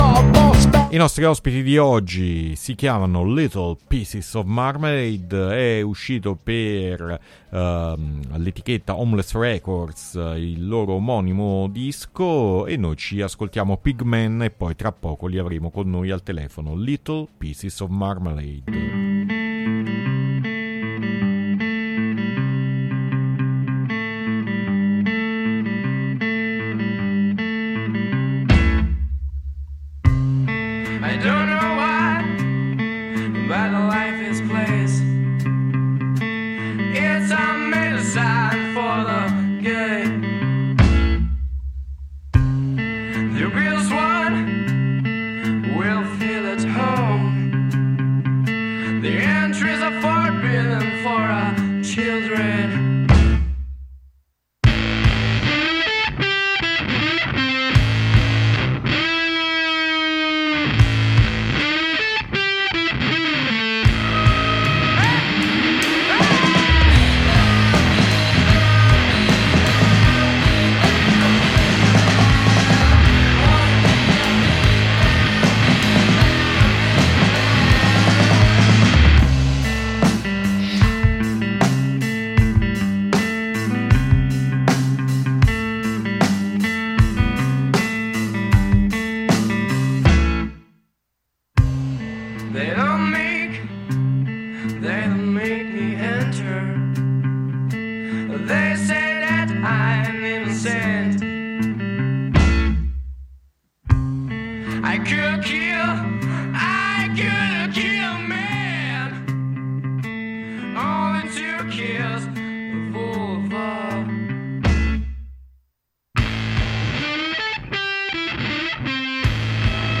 Intervista Little Pieces Of Marmelade 6-7-2020 | Radio Città Aperta